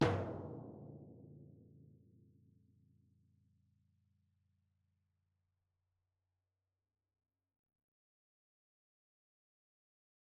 timpani1a-hit-v5-rr1-main.mp3